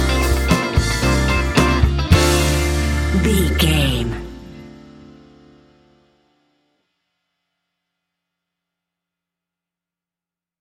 Ionian/Major
D
house
electro dance
synths
techno
trance